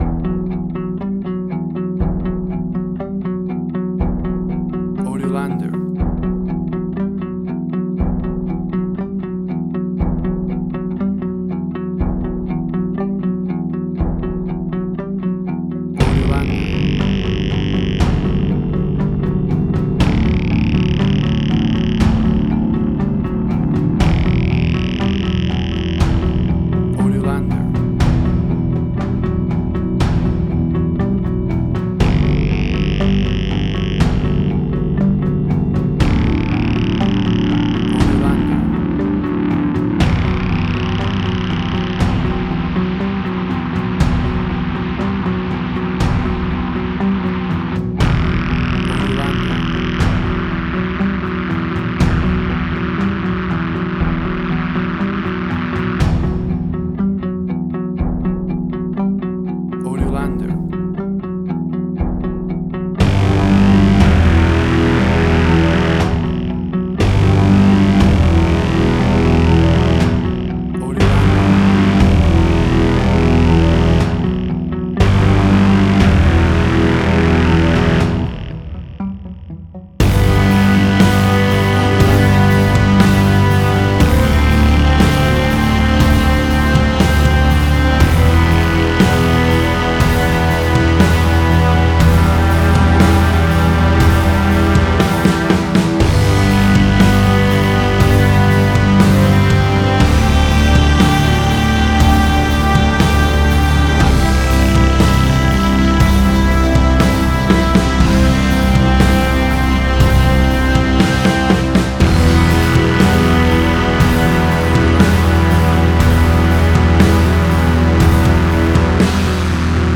Post-Electronic.
Tempo (BPM): 60